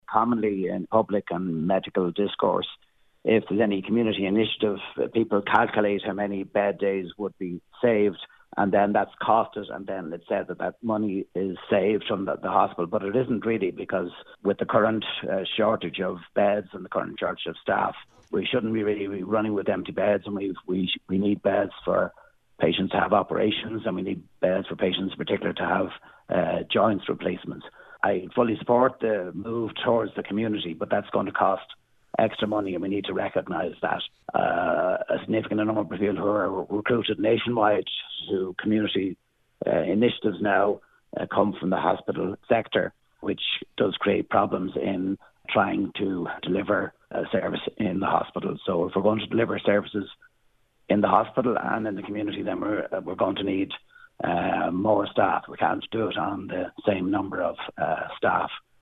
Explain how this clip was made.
told today’s Nine til Noon Show that ultimately more investment is needed: